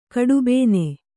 ♪ kaḍubēne